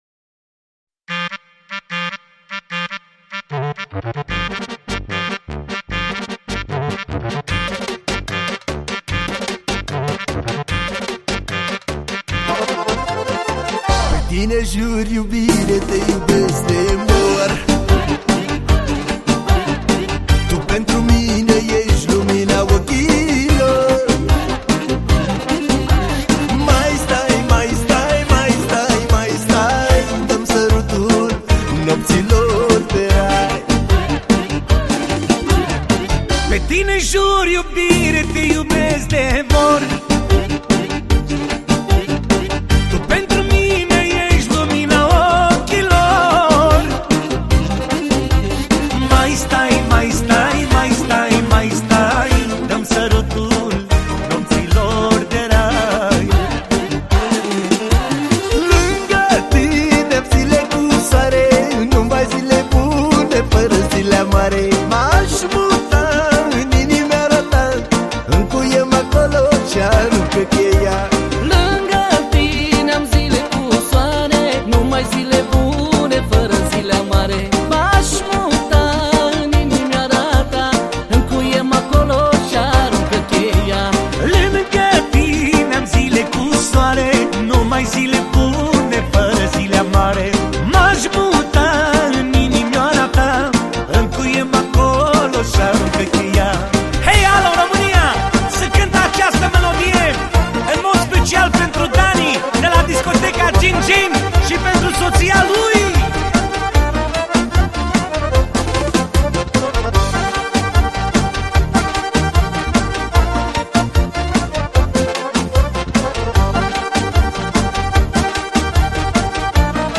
Manele Vechi